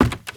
STEPS Wood, Creaky, Run 03.wav